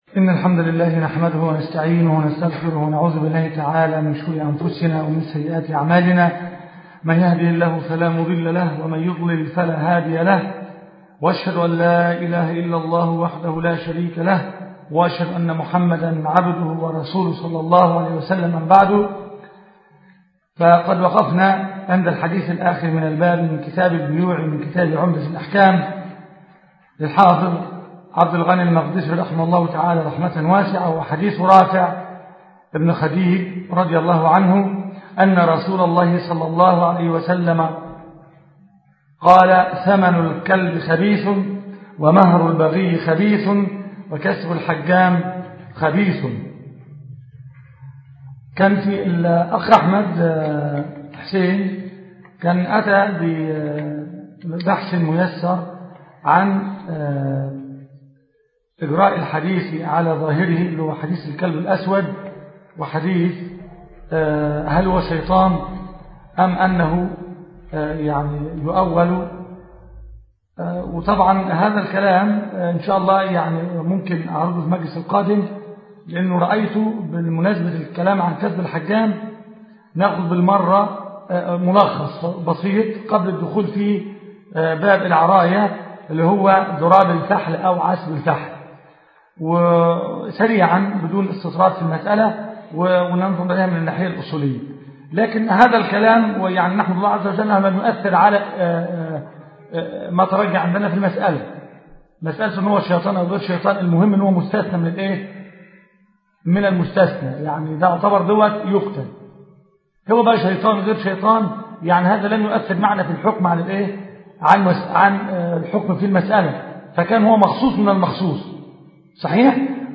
كتاب البيوع - المحاضرة السابعة والعشرون